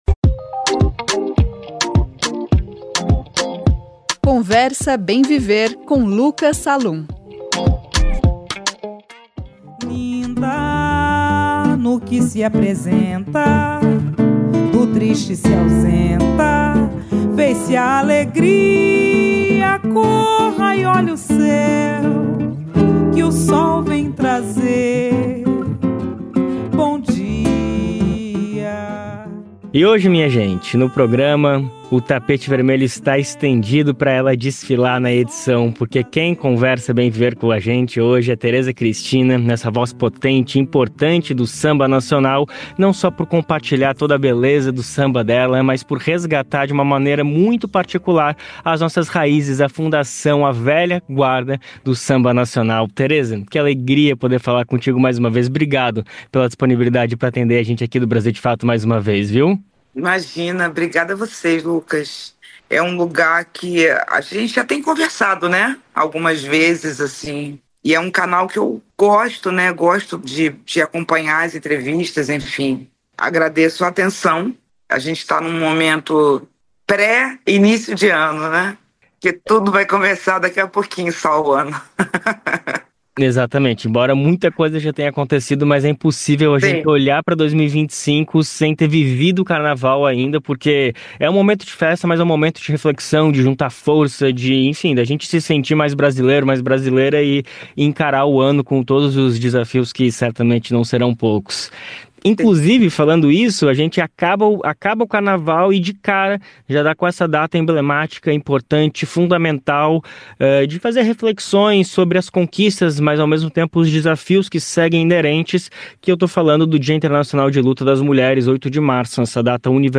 Em entrevista ao Conversa Bem Viver desta sexta-feira (7), a artista traça uma breve linha cronológica do samba carioca, rememorando a atuação de Hilária Batista de Almeida, a Tia Ciata, considerada a precursora das rodas no Brasil.